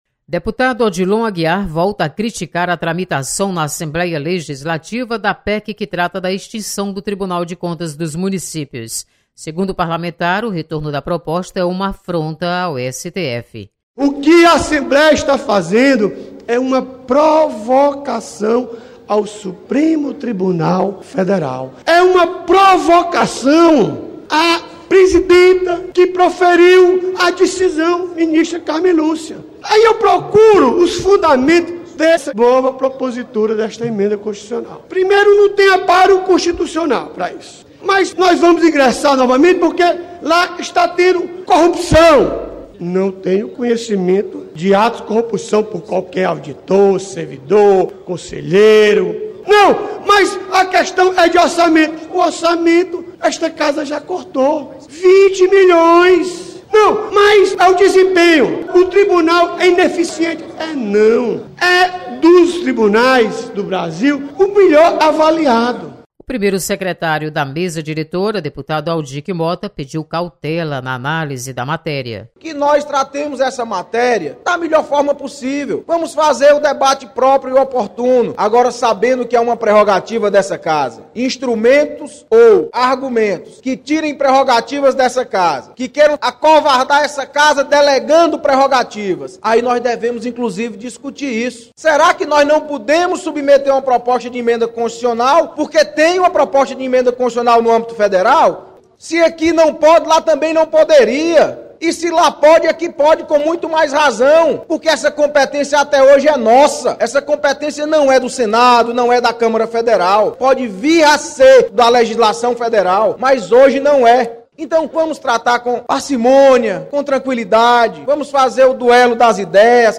FM Assembleia